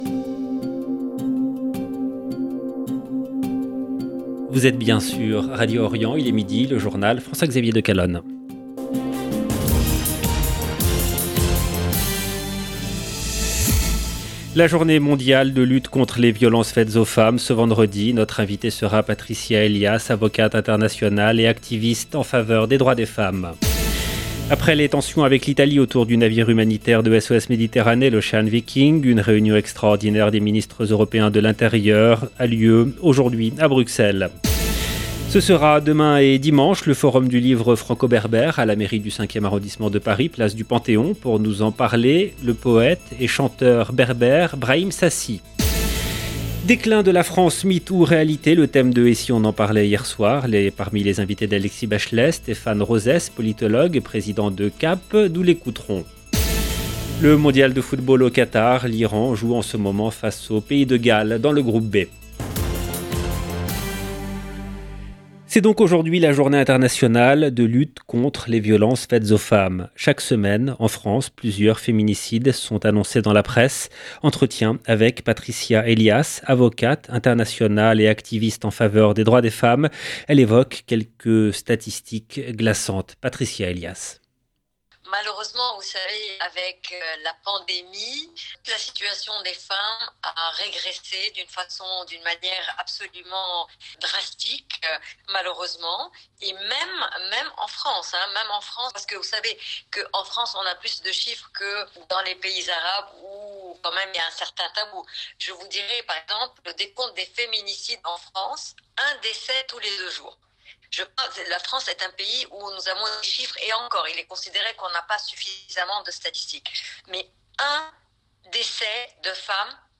LE JOURNAL DE MIDI EN LANGUE FRANCAISE DU 25/11/22